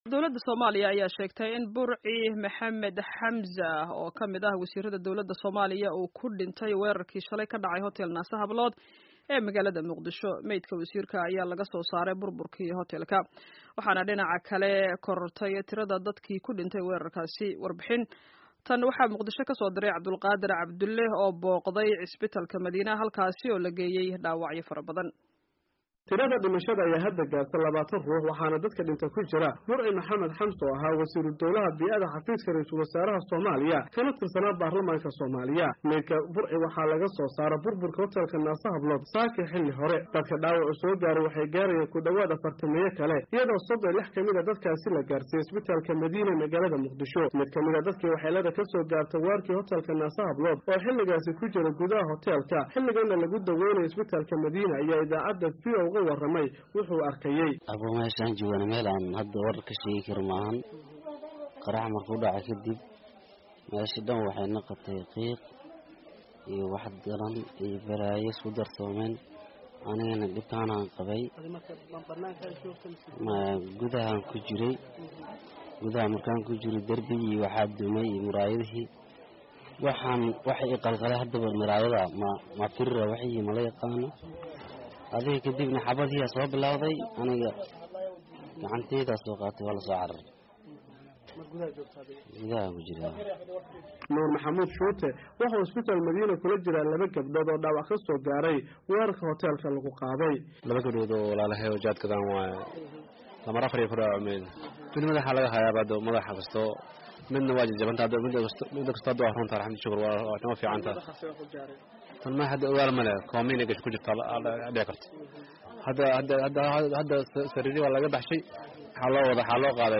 Warbixin: Weerarkii Hotel Naasa-Hablood